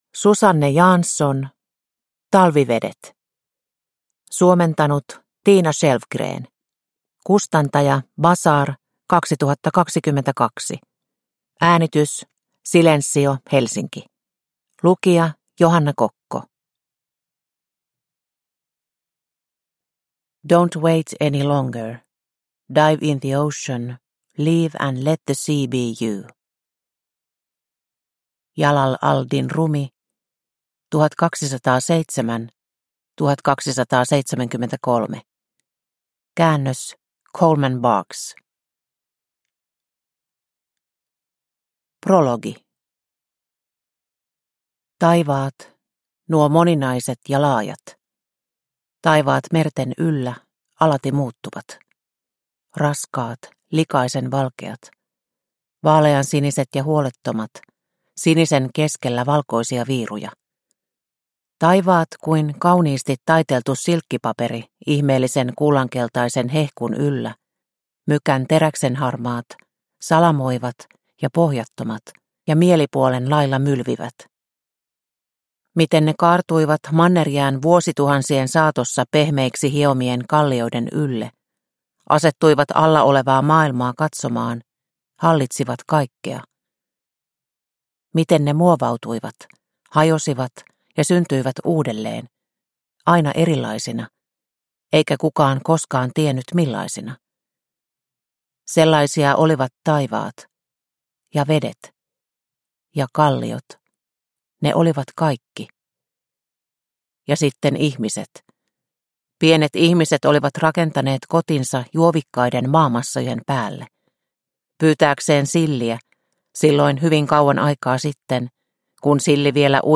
Talvivedet – Ljudbok – Laddas ner